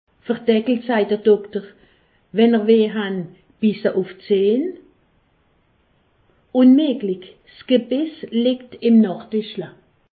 Haut Rhin
Ville Prononciation 68
Pfastatt